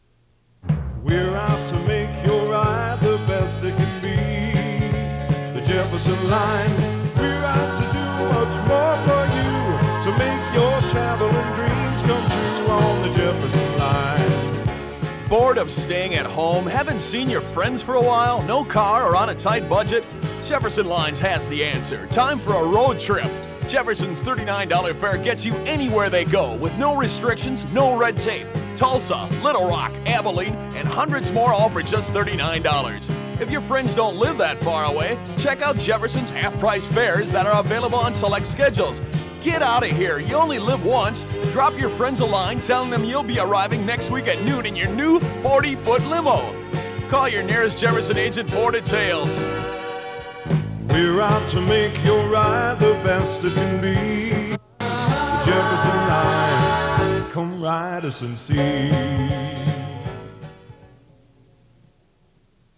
Jefferson Lines radio spot, undated. 1 master audio file (1 minute, 2 seconds): WAV (5.28 MB) and 1 user audio file: MP3 (1 MB).
Contains multiple radio spots, one for Jefferson bus lines and five for travel to Trinidad.